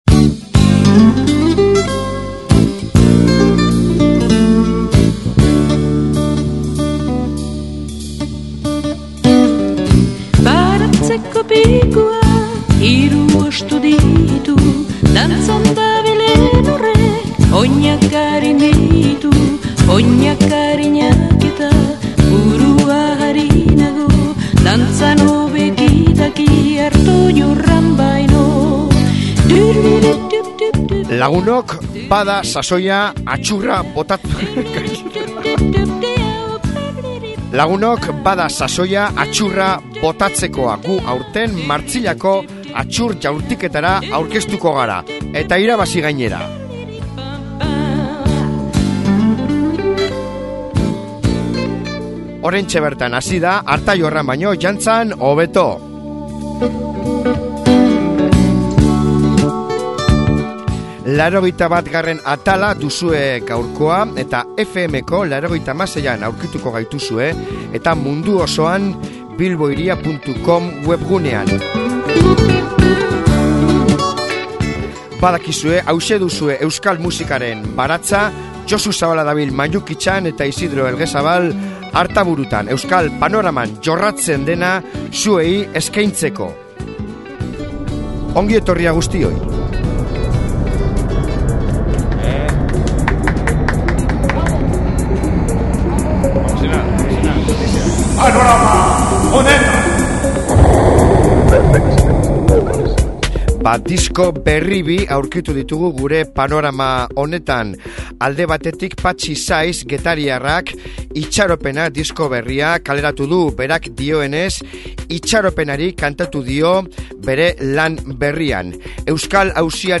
….triki apur batekin hasiko dugu saio hau;